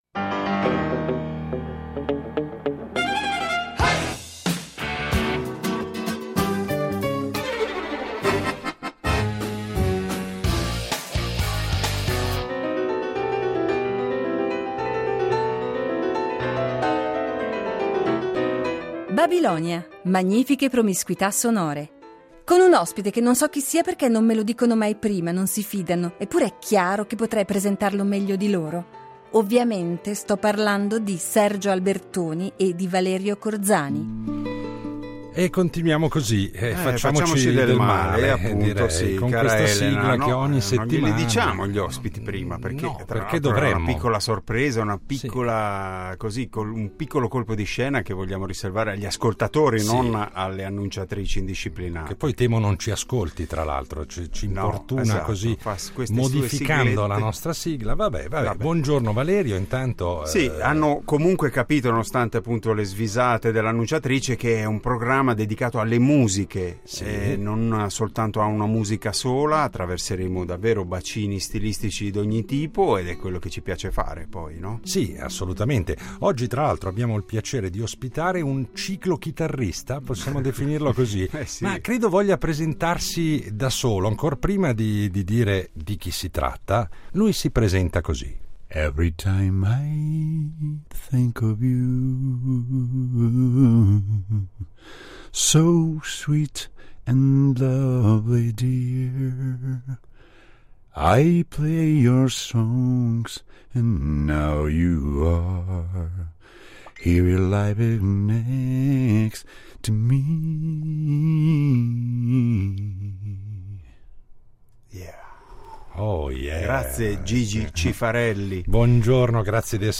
Difficile, lo avrete capito, inquadrare in poche righe e in una sola ora di trasmissione la sua scoppiettante personalità di uomo e di artista, ma come sempre ci proveremo a suon di ascolti e lo coinvolgeremo nelle nostre spericolate incursioni tra i generi. Cercheremo insomma di farlo parlare di tutto un po', dal suo amore per la chitarra di Wes Montgomery al rapporto privilegiato con Mina e quindi con Lugano.